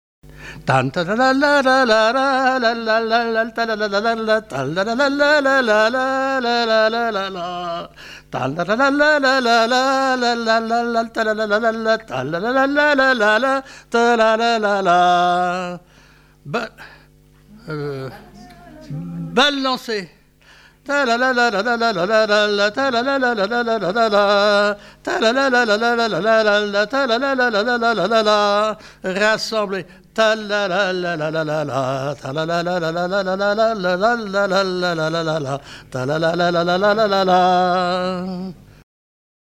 avant-deux du quadrille
danse : quadrille : avant-deux
Répertoire de chansons populaires et traditionnelles
Pièce musicale inédite